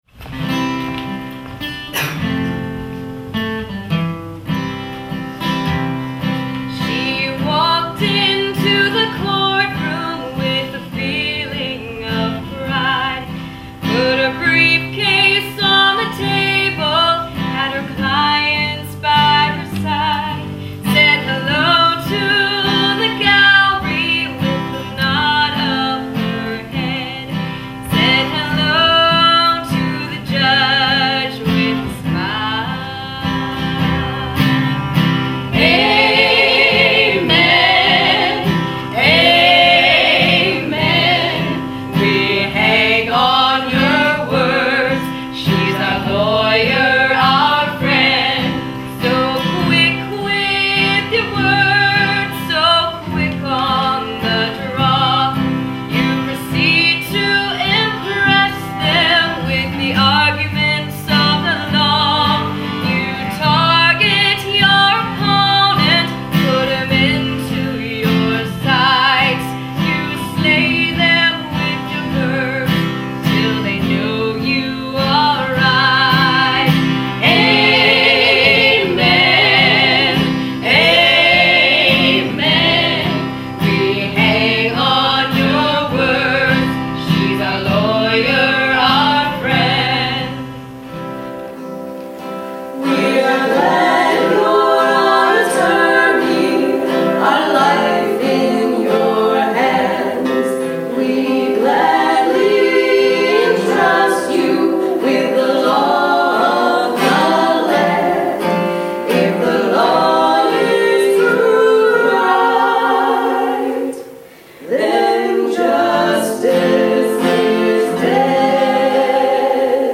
The new ‘roots’ musical about community and family.